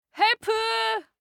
알림음 8_한국식헬프1-여자.mp3